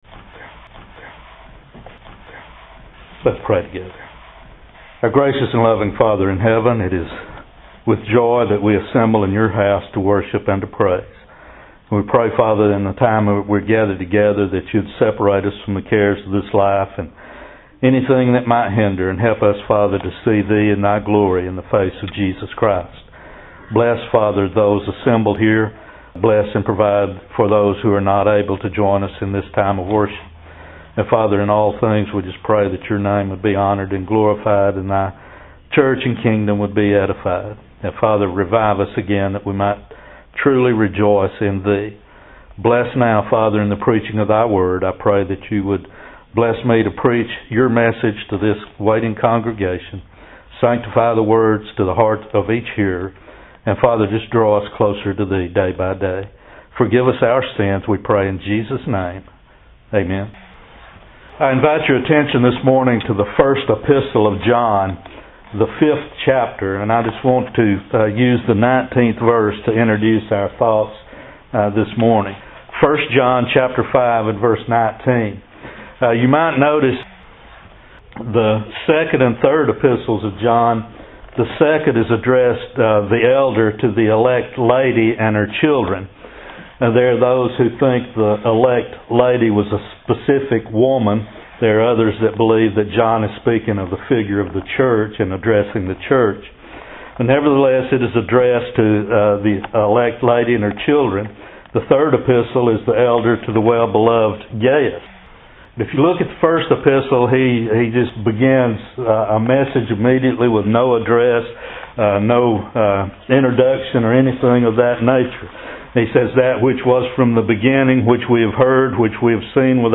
Sermon by Speaker